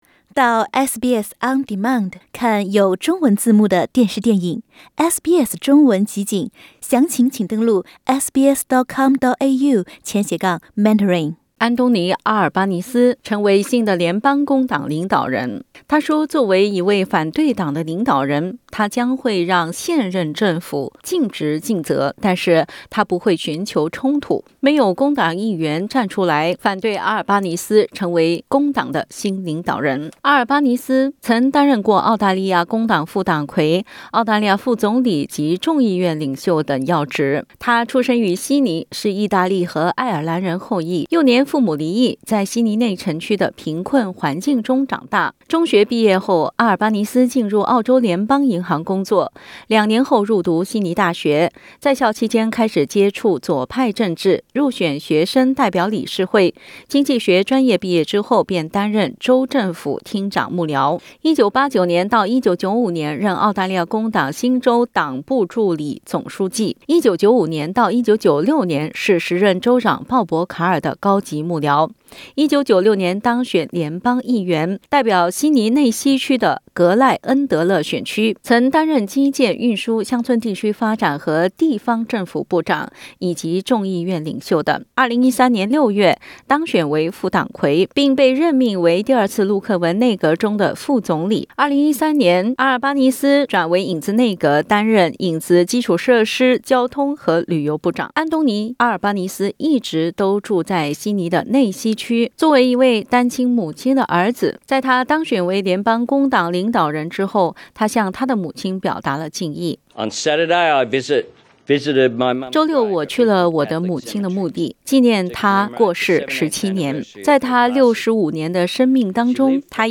Incoming federal Labor leader Anthony Albanese addresses the media.